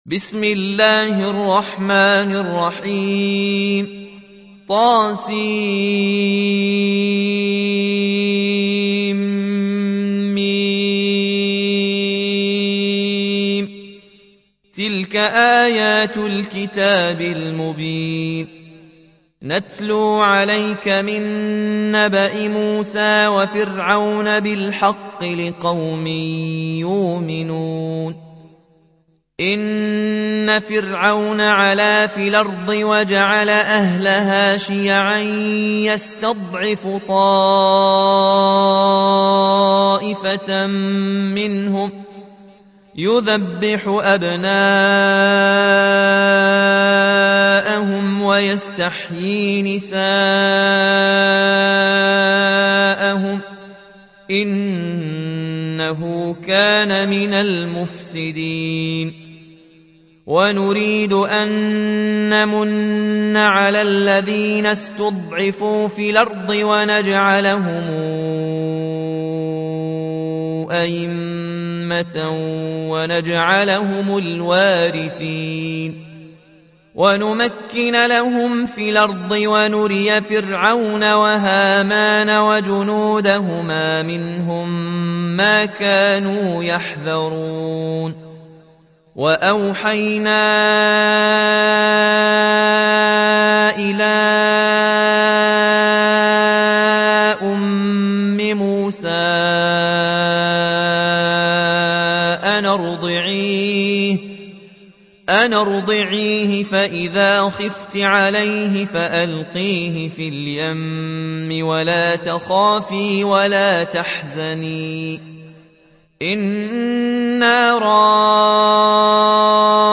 28. سورة القصص / القارئ
القرآن الكريم